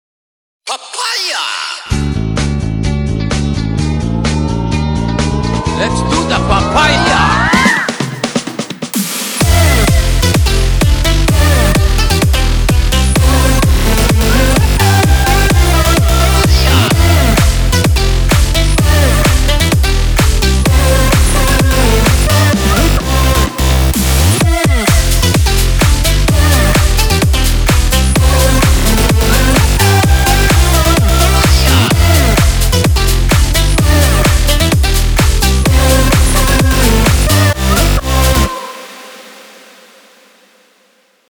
Music / House